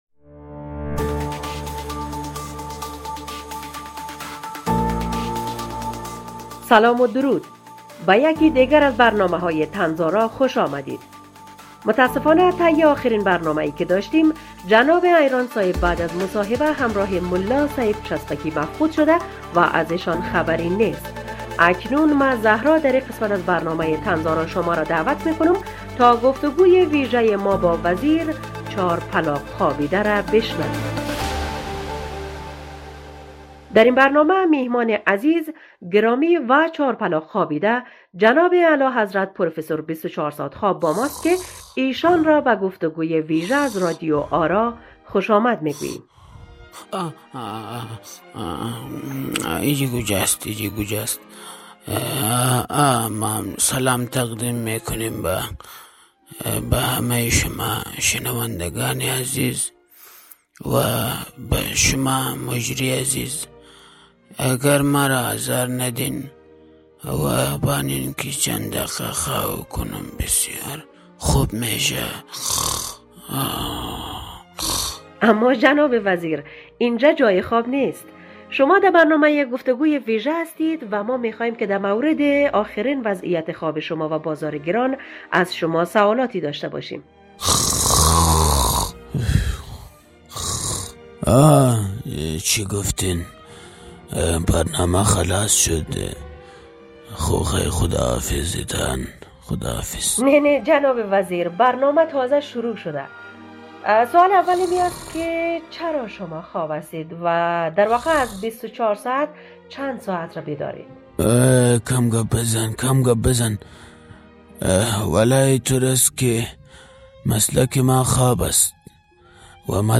گفت و گوی ویژه با وزیر چاپلاق خوابیده!